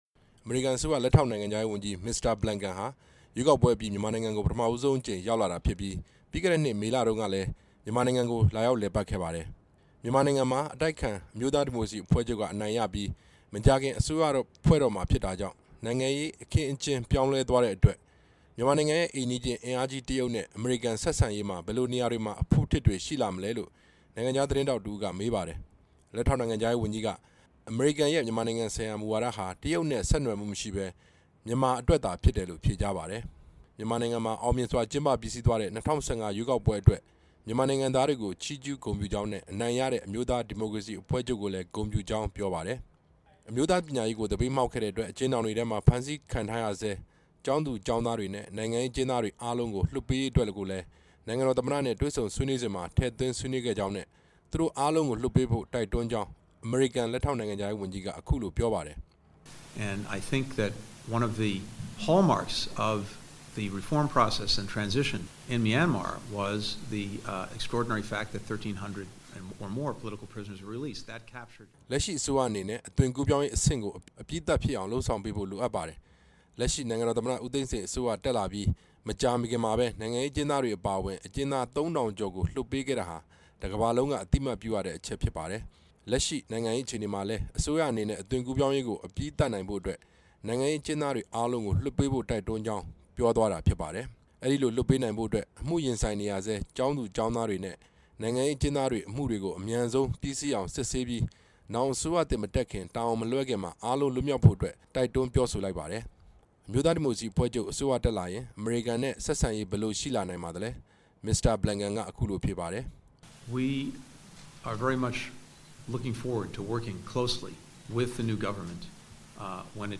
ညနေပိုင်းမှာ နေပြည်တော် Kempinski ဟိုတယ်မှာ သတင်းစာရှင်းလင်းပွဲကျင်းပခဲ့ပြီး ဆွေးနွေးမှုတွေအကြောင်းနဲ့ သတင်းမီဒီယာတွေ မေးမြန်း တာကို ဖြေကြားခဲ့ပါတယ်။